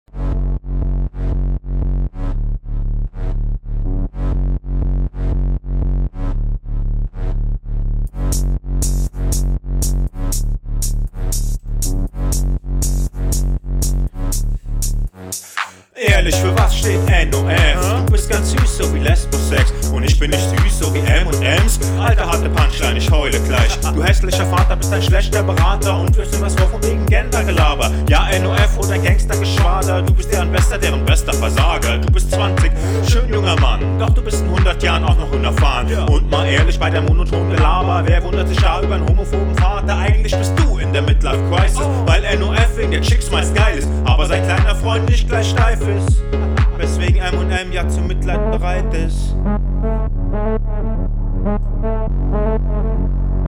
hab befürchtet, dass du zu leise bist auf dem beat und is wieder passiert haha …